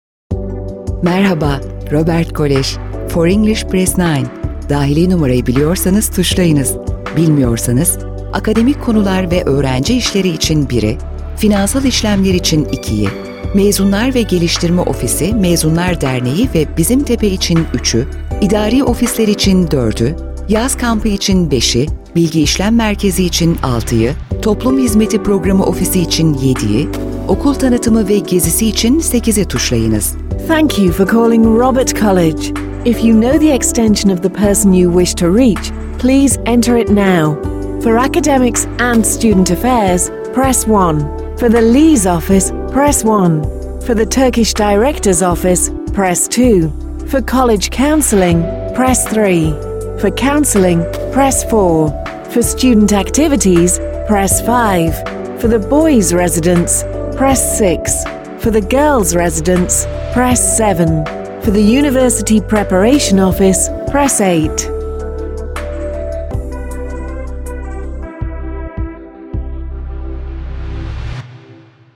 IVR Anons Seslendirme
IVR Anons Seslendirme Hizmeti